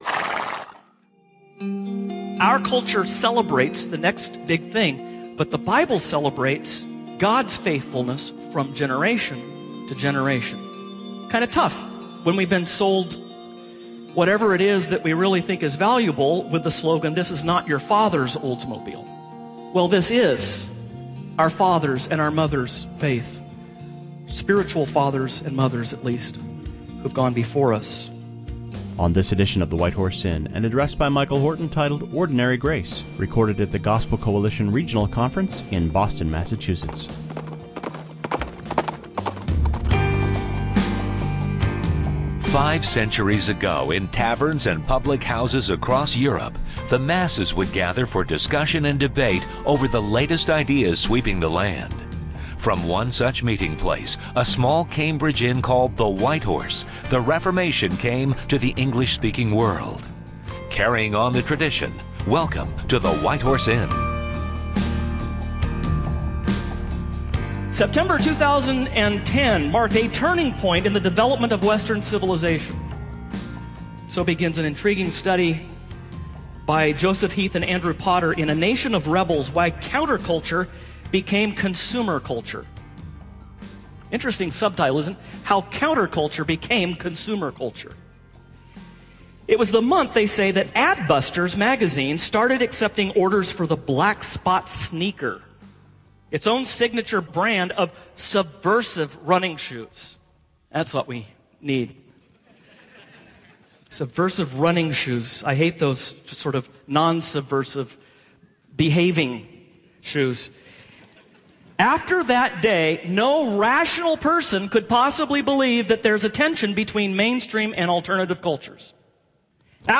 On this special edition of the program, Michael Horton calls us back to an appreciation of ordinary grace that can sustain us for the long-term. Recorded at a recent Gospel…
Ordinary Grace Featuring: Michael Horton Release date: June 28, 2015 Event(s): The Gospel Coalition Conference Topic(s): God The Grace Of God Format(s): Audio Download: Episode Audio (mp3)